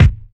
• 2000s Clean Kick Single Shot F Key 320.wav
Royality free kick drum tuned to the F note. Loudest frequency: 311Hz